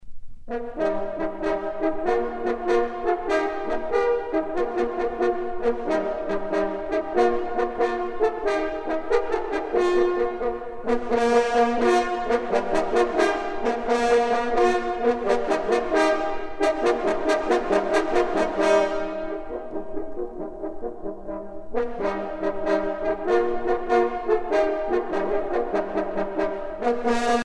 circonstance : vénerie
Pièce musicale éditée